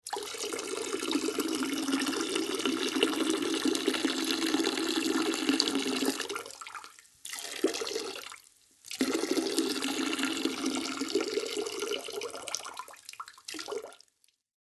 На этой странице собраны различные звуки мочеиспускания в высоком качестве.
Звук мужчины писающего в унитаз делает пи-пи